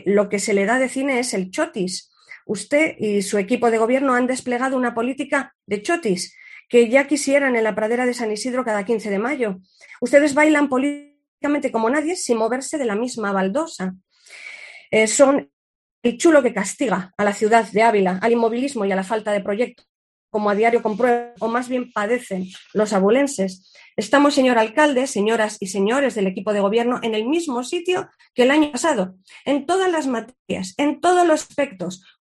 Sonsoles Sánchez-Reyes, portavoz PP. Pleno estado debate ciudad